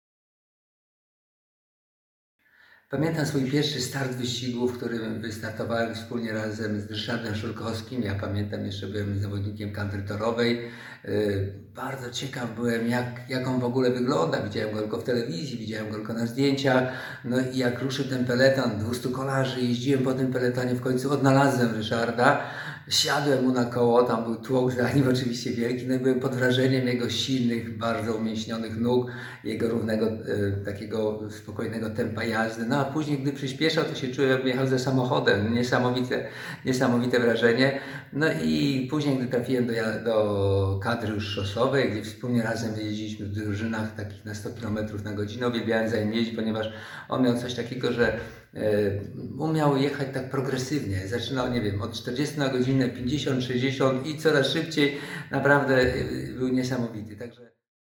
Tak wspomina Ryszarda Szurkowskiego, inna legenda polskiego kolarstwa, Czesław Lang.
MP3-Lang-o-Szurkowskim..mp3